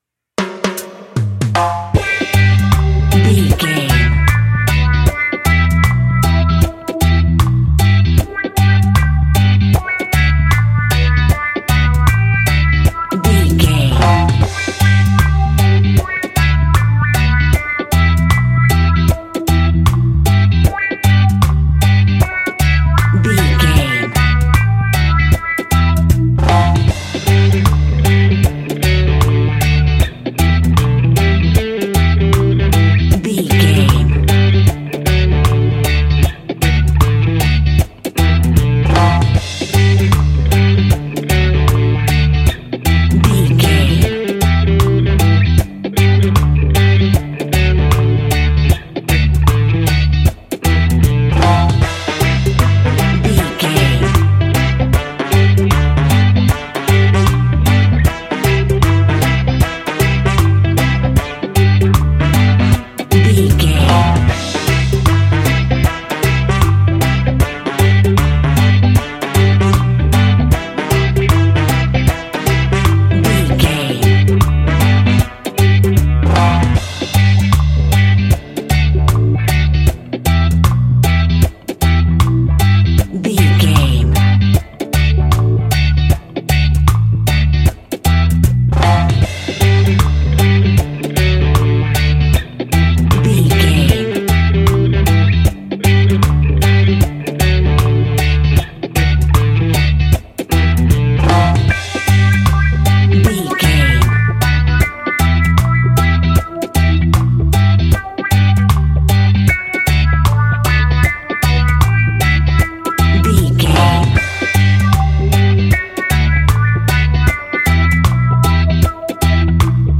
Classic reggae music with that skank bounce reggae feeling.
Aeolian/Minor
reggae instrumentals
laid back
chilled
off beat
drums
skank guitar
hammond organ
percussion
horns